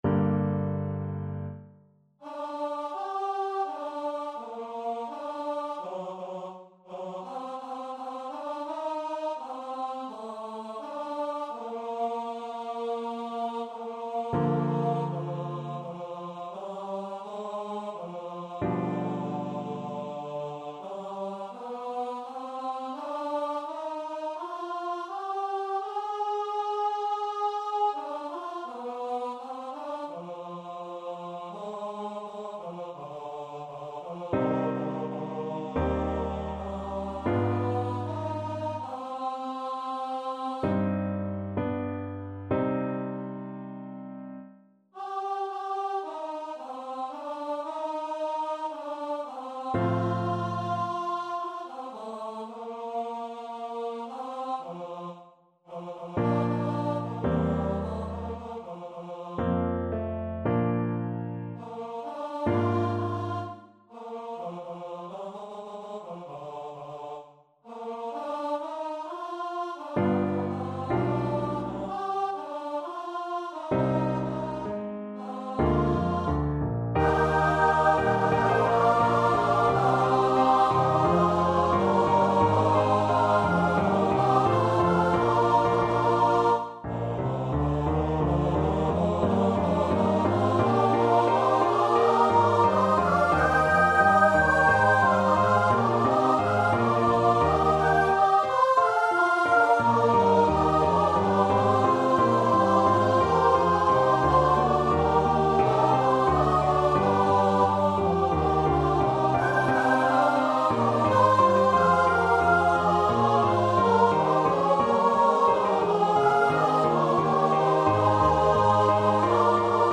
Classical Bach, Johann Sebastian 76. Und Joseph nam den Leib; Herr, wir haben gedacht (St. Matthew Passion) Choir version
Choir  (View more Intermediate Choir Music)
Classical (View more Classical Choir Music)